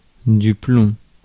Ääntäminen
France: IPA: [plɔ̃]